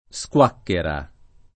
squacchera [ S k U# kkera ]